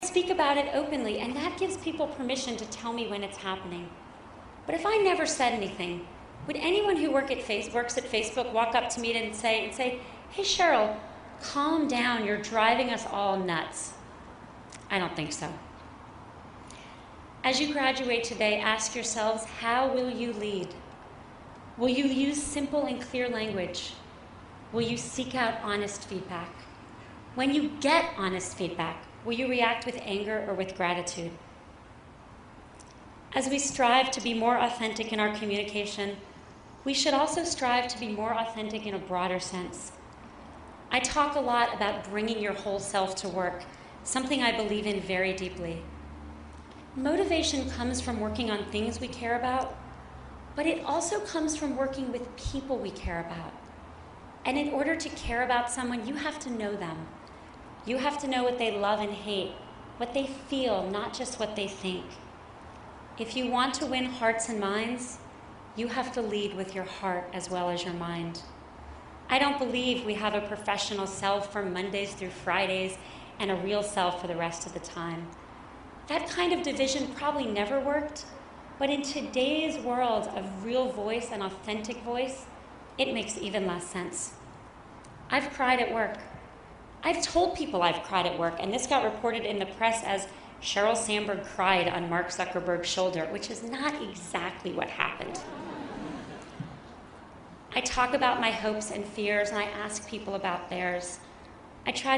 公众人物毕业演讲第185期:桑德伯格2012哈佛商学院(10) 听力文件下载—在线英语听力室